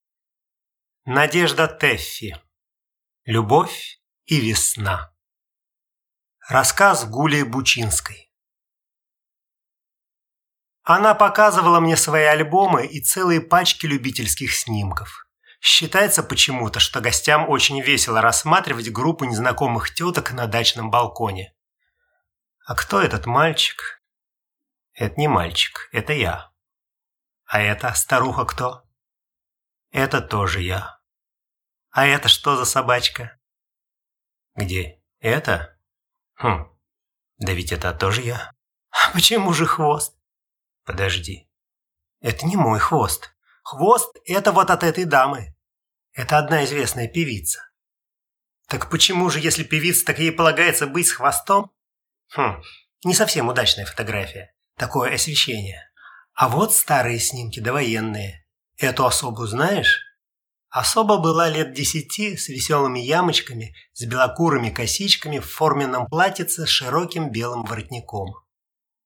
Aудиокнига Любовь и весна